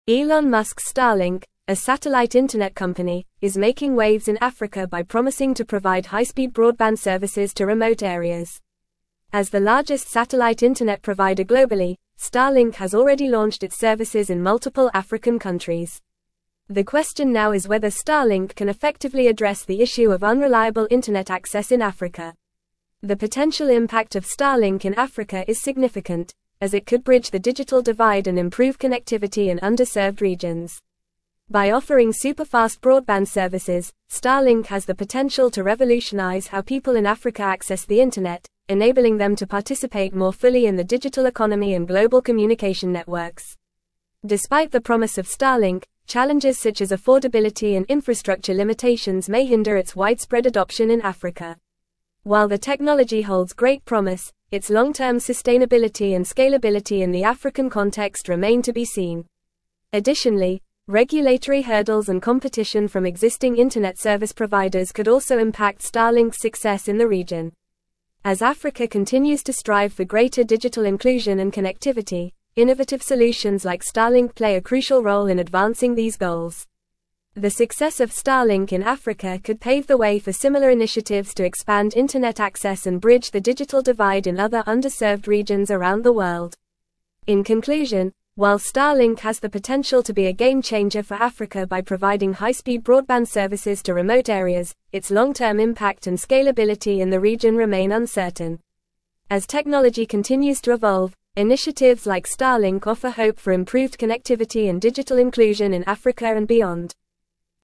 Fast
English-Newsroom-Advanced-FAST-Reading-Starlink-Revolutionizing-African-Connectivity-with-Satellite-Internet.mp3